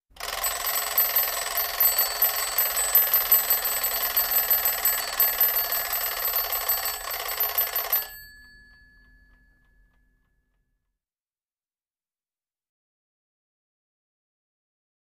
Clock Alarm Old 3; Rings With Bell And Ratty Fast Clunks 2